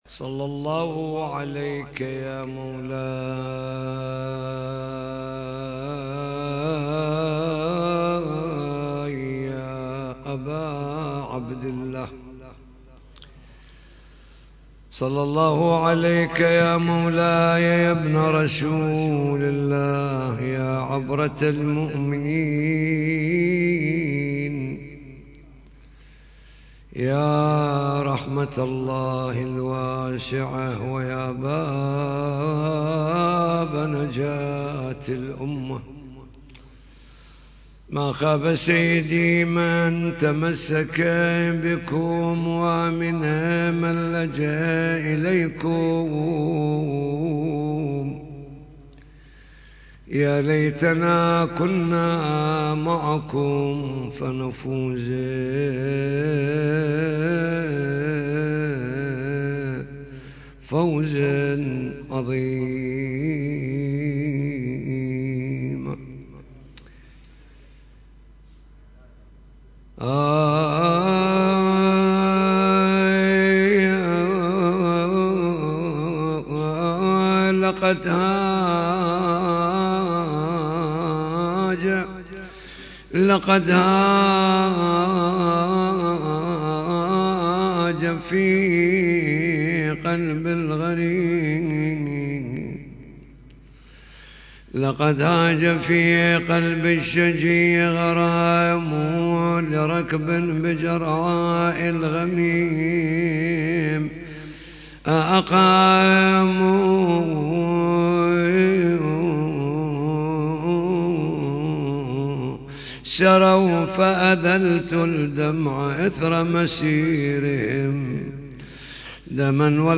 محاضرة ليلة 19 جمادى الأولى